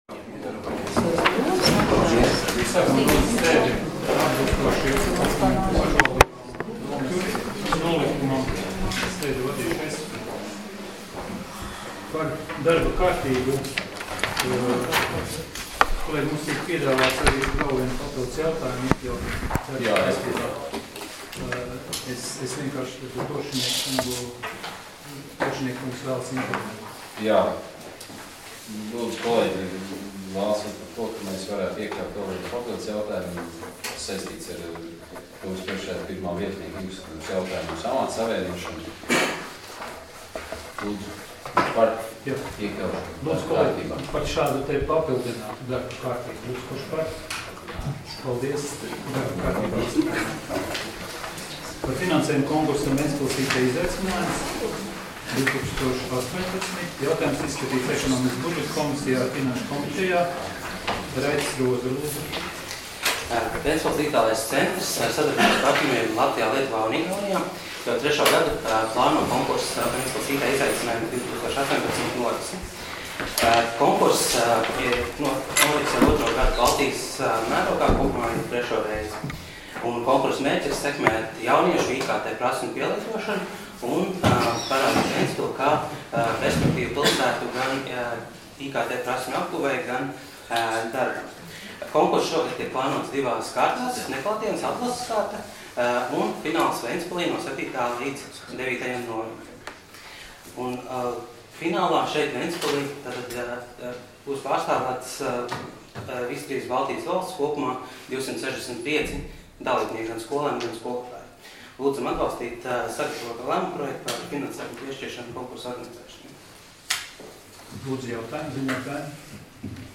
Domes sēdes 14.09.2018. audioieraksts